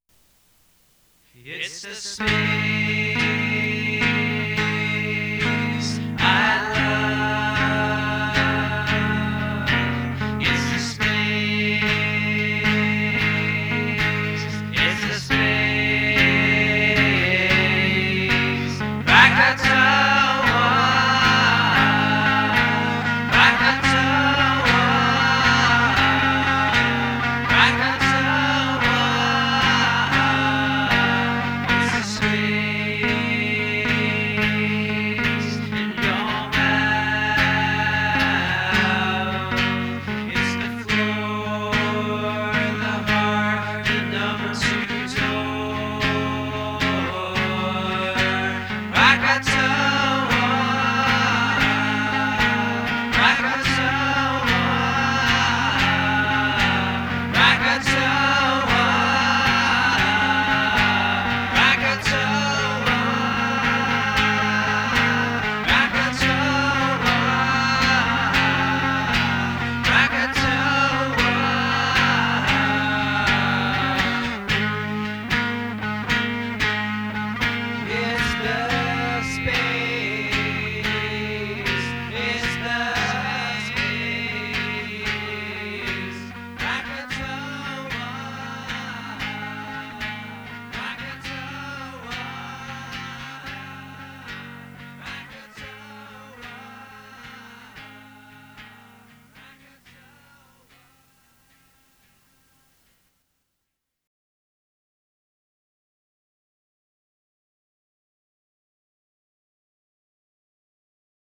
Enjoy the low-fi songs of yearning.